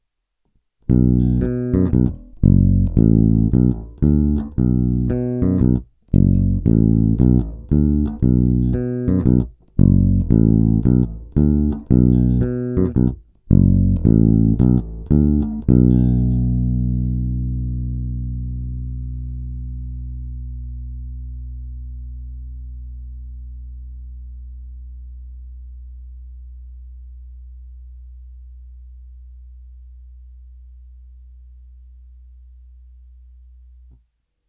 Nejstarší kousek má i nejdřevitější zvuk.
Následující nahrávky byly provedeny rovnou do zvukovky. Použité struny byly nějaké niklové padesátky ve slušném stavu.